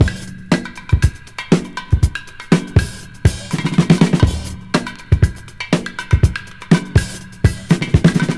Drum loops
Original creative-commons licensed sounds for DJ's and music producers, recorded with high quality studio microphones.
114-bpm-drum-loop-sample-d-sharp-key-iEQ.wav